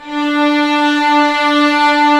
Index of /90_sSampleCDs/Roland L-CD702/VOL-1/STR_Vlns 6 mf-f/STR_Vls6 f amb